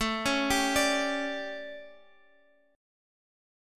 Listen to A11 strummed